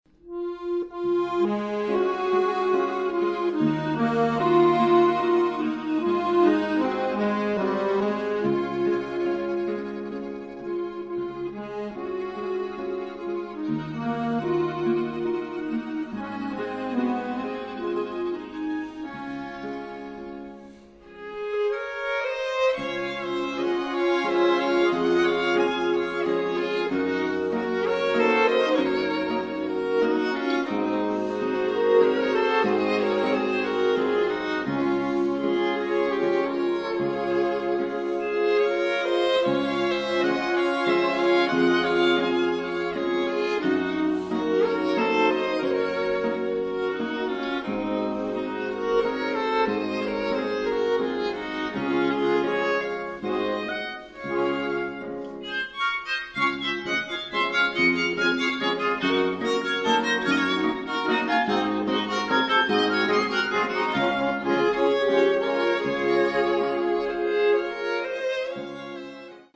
** Quartett mit Knopfharmonika
Steinerner Saal, Musikverein Wien